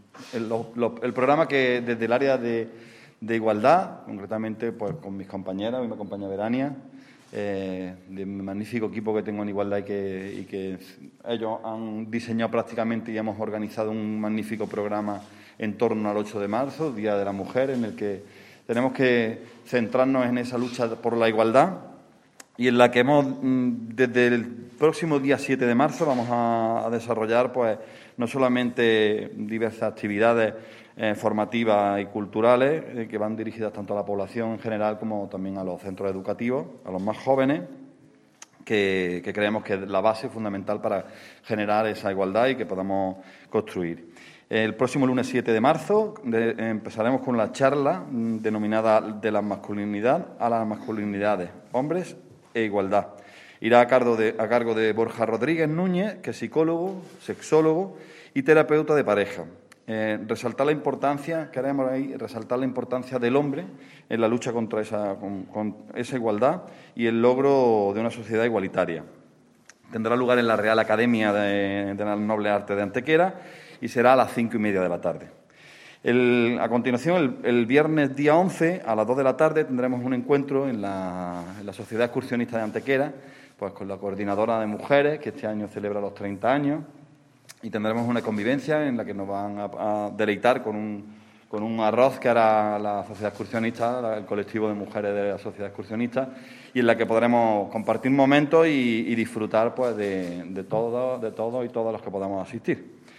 El teniente de alcalde delegado de Programas Sociales e Igualdad, Alberto Arana, ha presentado hoy viernes en rueda de prensa el programa de actividades conmemorativas en nuestra ciudad que se llevarán a cabo en torno a la próxima conmemoración del Día Internacional de la Mujer el martes 8 de marzo.
Cortes de voz